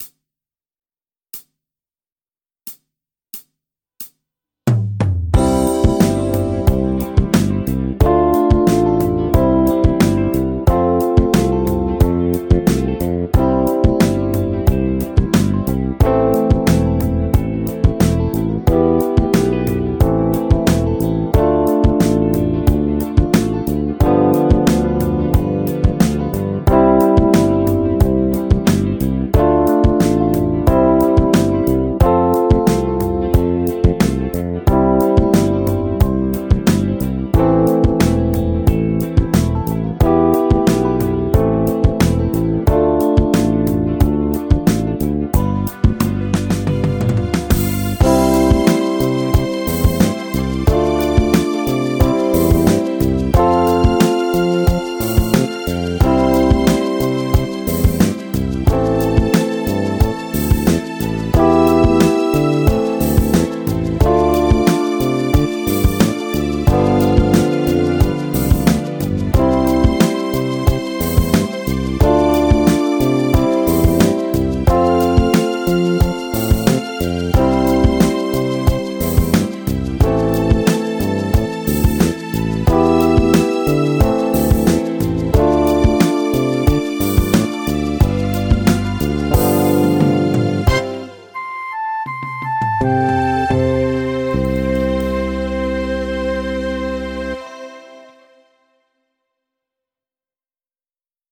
ヨナ抜きメジャー・スケール ギタースケールハンドブック -島村楽器